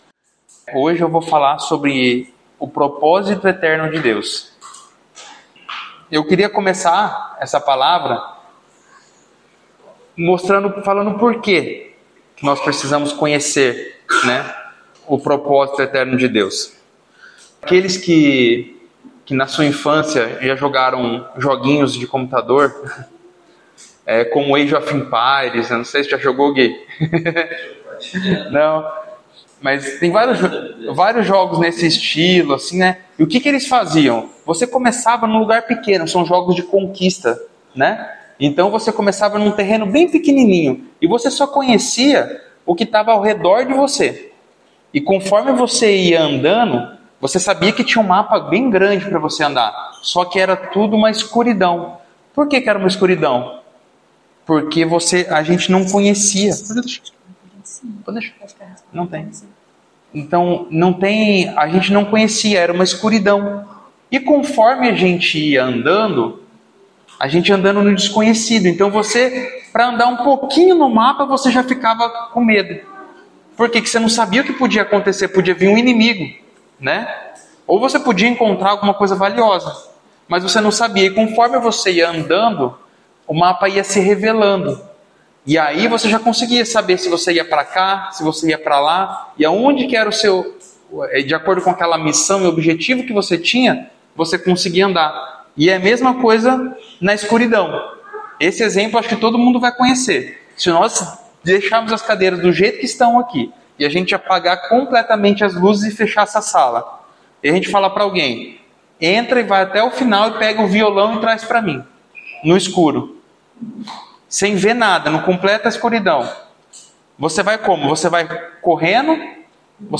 Palavra ministrada
no culto do dia 02/03/2025